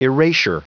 Prononciation du mot erasure en anglais (fichier audio)
Prononciation du mot : erasure